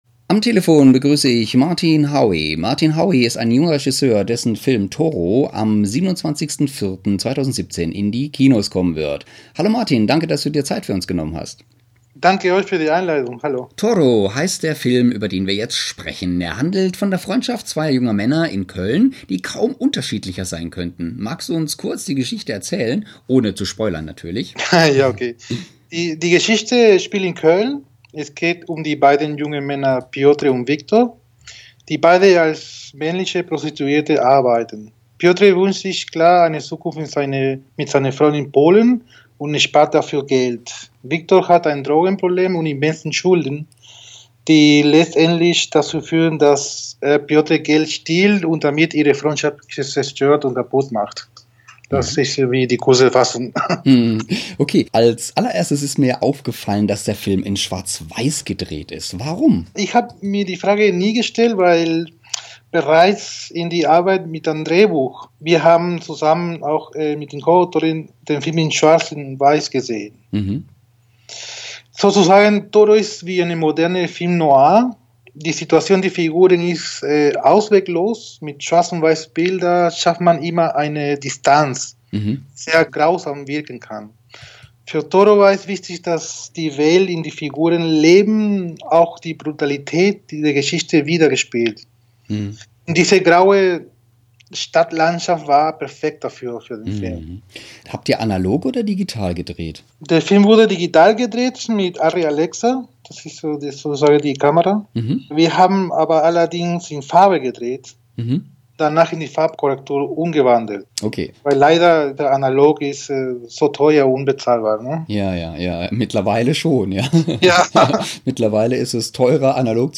Zwei Top-Gäste bei uns in der Sendung: